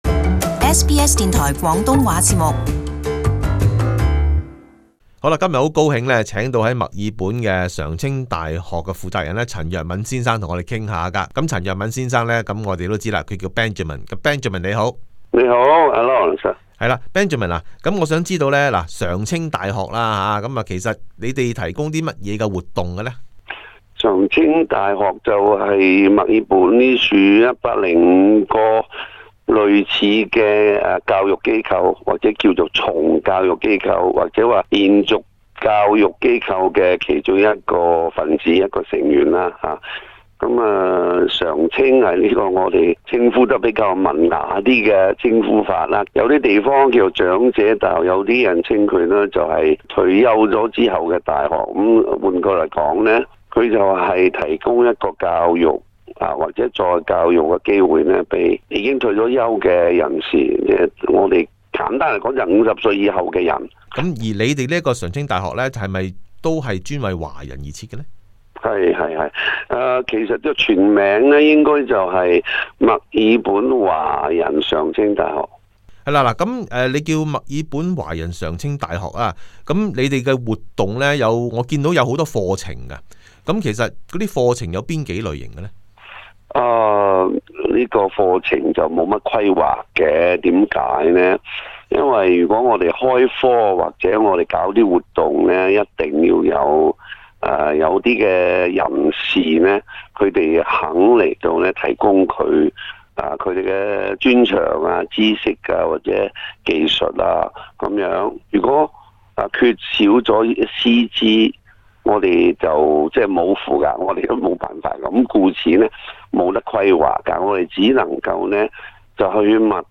【社團專訪】墨爾本華人常青大學